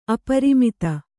♪ aparimita